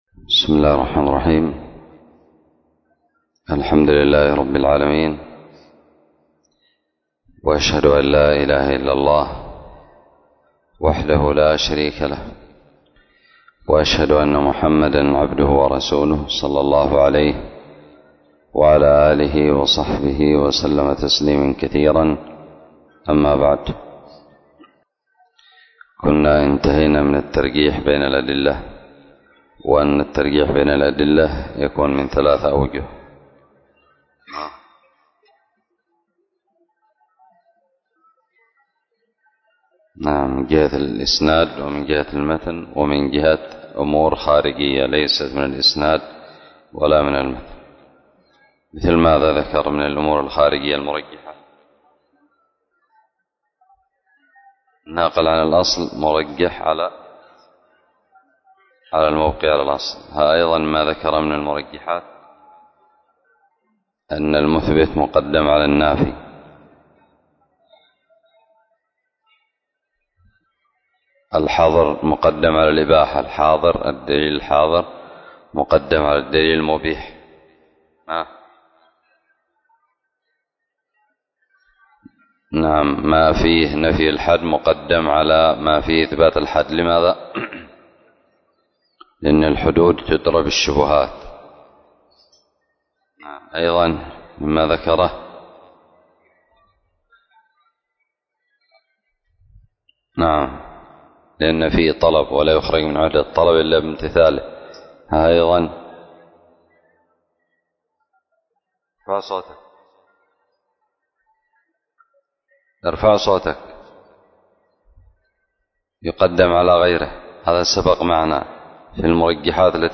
الدرس السادس عشر بعد المائة من شرح مذكرة أصول الفقه
ألقيت بدار الحديث السلفية للعلوم الشرعية بالضالع